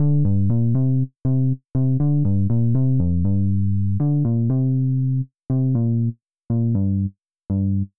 描述：欢乐在我的破贝司上做了这个
标签： 120 bpm Electronic Loops Bass Guitar Loops 1.35 MB wav Key : Unknown
声道立体声